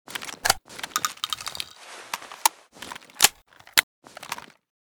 bm16_reload_empty.ogg